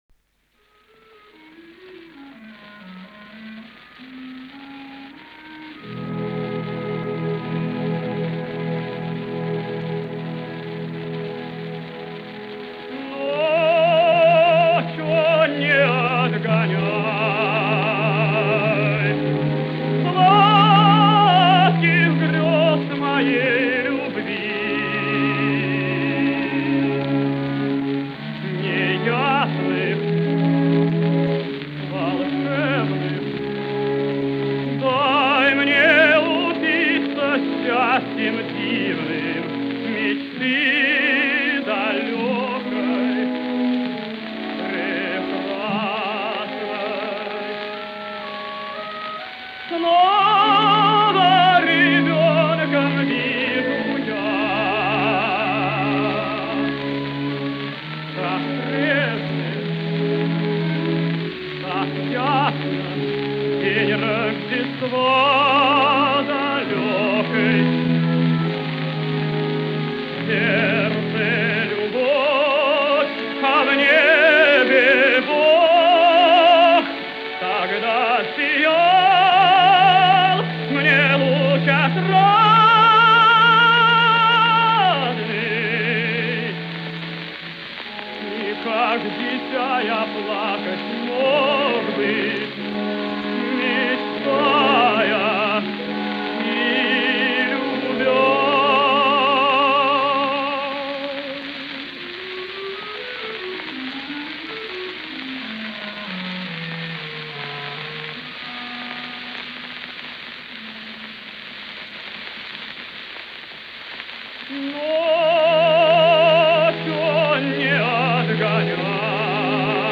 Арии из опер.